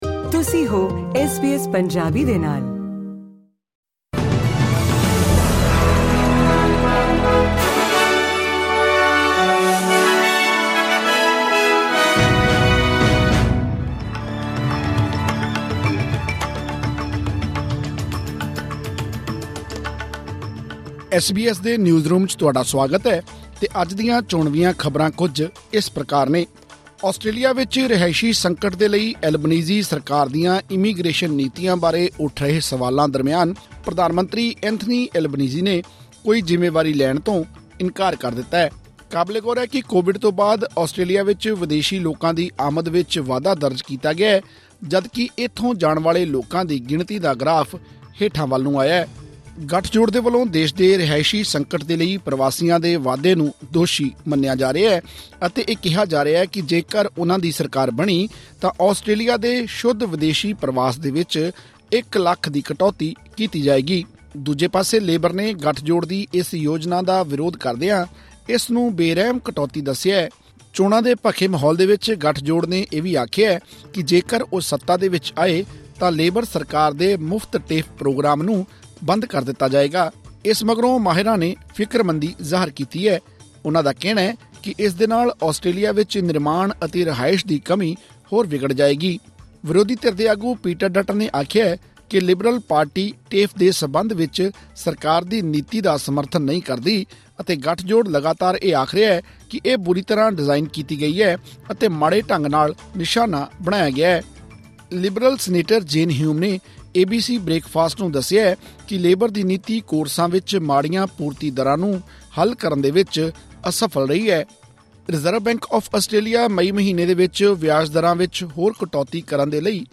ਆਸਟ੍ਰੇਲੀਆ ਵਿੱਚ ਰਿਹਾਇਸ਼ੀ ਸੰਕਟ ਦੇ ਲਈ ਅਲਬਾਨੀਜ਼ੀ ਸਰਕਾਰ ਦੀਆਂ ਇਮੀਗ੍ਰੇਸ਼ਨ ਨੀਤੀਆਂ ਬਾਰੇ ਉੱਠ ਰਹੇ ਸਵਾਲਾਂ ਦਰਮਿਆਨ ਪ੍ਰਧਾਨ ਮੰਤਰੀ ਐਂਥਨੀ ਅਲਬਾਨੀਜ਼ੀ ਨੇ ਕੋਈ ਜਿੰਮੇਵਾਰੀ ਲੈਣ ਤੋਂ ਇਨਕਾਰ ਕਰ ਦਿੱਤਾ ਹੈ। ਕਾਬਲੇਗੌਰ ਹੈ ਕਿ ਕੋਵਿਡ ਤੋਂ ਬਾਅਦ ਆਸਟ੍ਰੇਲੀਆ ਵਿੱਚ ਵਿਦੇਸ਼ੀ ਲੋਕਾਂ ਦੀ ਆਮਦ ਵਿੱਚ ਵਾਧਾ ਦਰਜ ਕੀਤਾ ਗਿਆ ਹੈ ਜਦਕਿ ਇੱਥੋਂ ਜਾਣ ਵਾਲੇ ਲੋਕਾਂ ਦੀ ਗਿਣਤੀ ਦਾ ਗਰਾਫ ਹੇਠਾਂ ਵੱਲ ਨੂੰ ਆਇਆ ਹੈ। ਇਹ ਅਤੇ ਹੋਰ ਅਹਿਮ ਖ਼ਬਰਾਂ ਲਈ ਸੁਣੋ ਇਹ ਆਡੀਓ ਰਿਪੋਰਟ